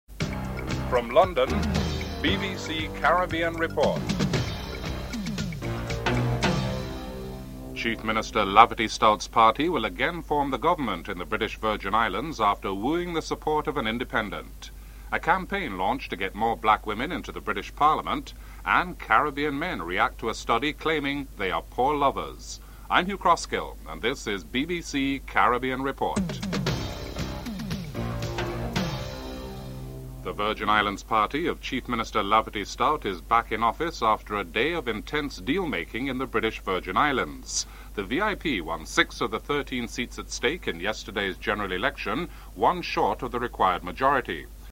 1. Headlines
6. Recap of top stories (14:43-15:03)